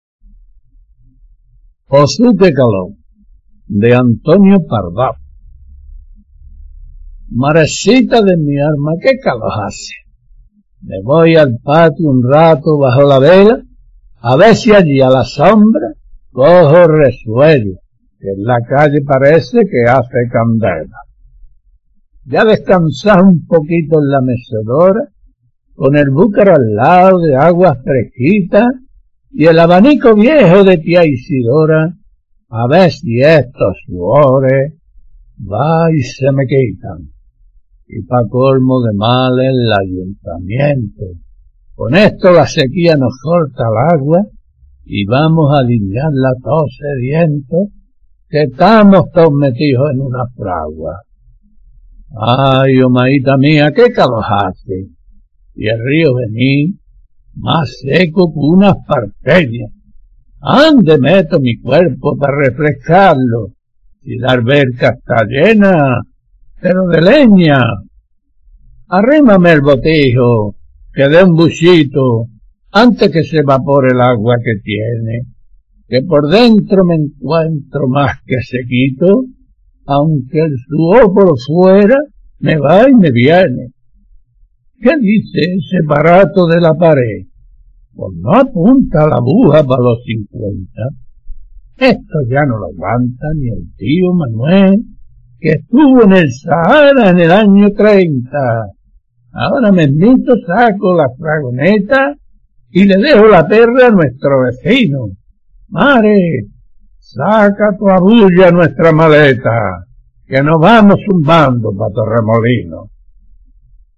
Son una maravilla de fidelidad al andaluz castizo, campero, que habla de verdad nuestra gente.
Resulta una verdadera pasado escuchar las mismas con el fondo del toque de una guitarra.